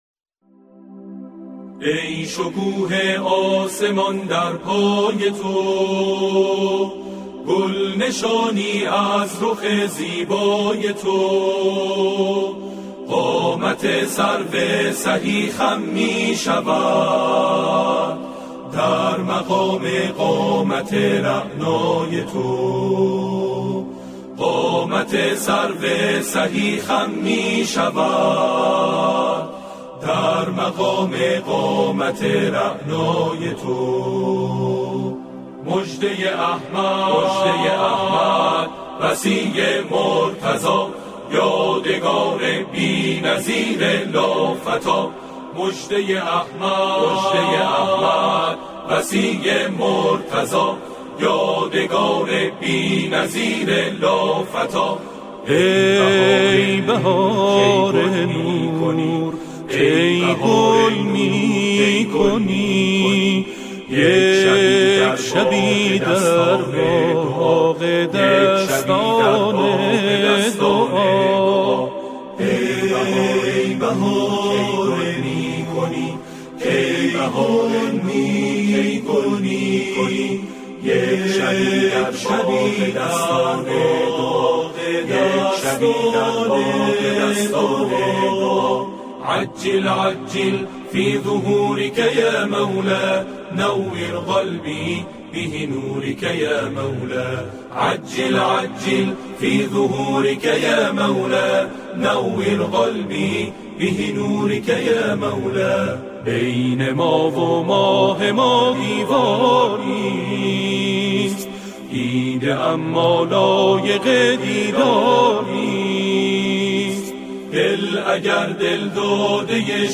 گروه کر به صورت آکاپلا اجرا می‌کند.